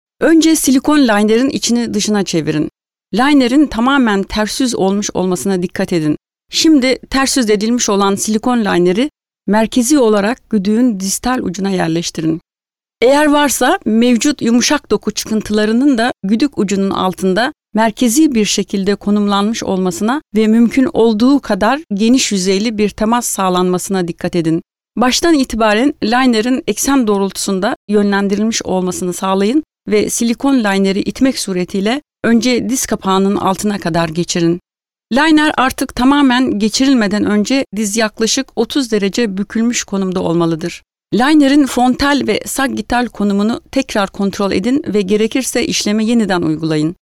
Sprecherin türkisch. Muttersprachliches Istanbuler Hochtürkisch.
Sprechprobe: Werbung (Muttersprache):
turkish female voice over artist